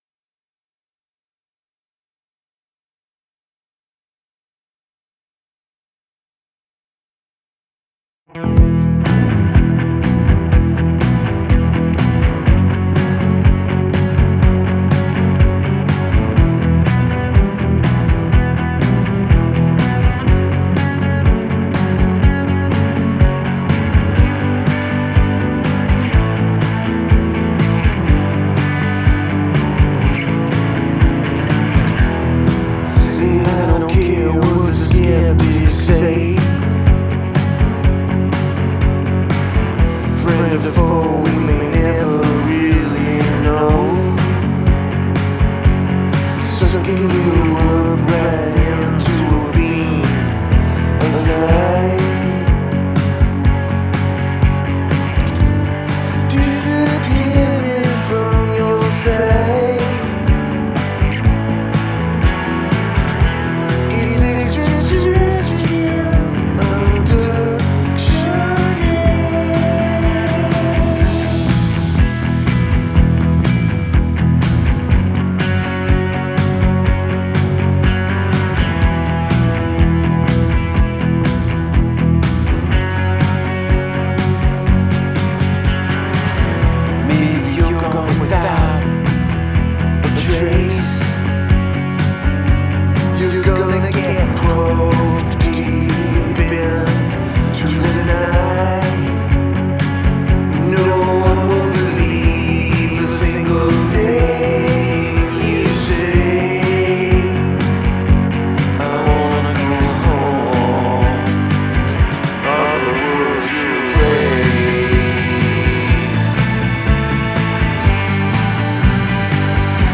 Grimy, gritty alien rock.
I love it, especially the synths, backing vocals and vocal treatment on the lead vocal.
Love the guitar in the intro.
That steady chugging guitar works perfectly with your vocal. I love the false ending thing and how it comes back for just one more little abduction raid and then ends superabruptly.
I love the slapback effect on your voice. I dig the surf-rock vibe that I love on this song.
String synths add a nice touch. I like the menacing drive to accompany the possible abduction fear, and then the instrumental tag ending abruptly…ope, abducted!